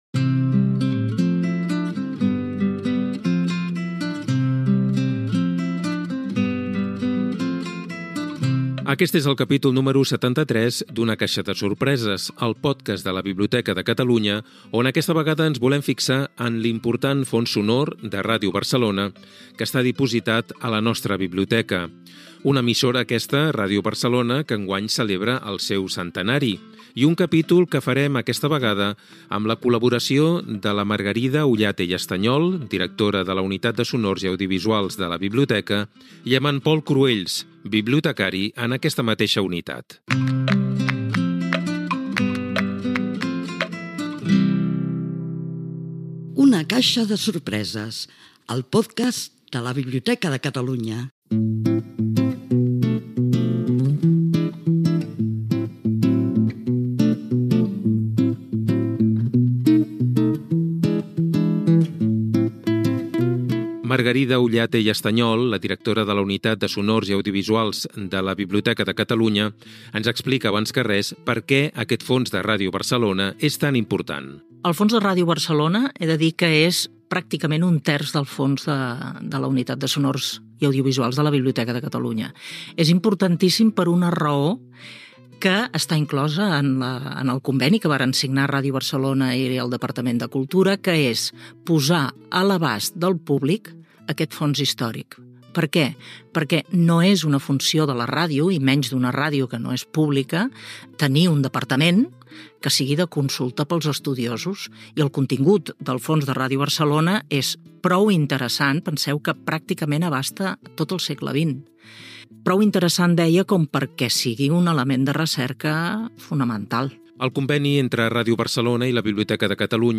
Gènere radiofònic Cultura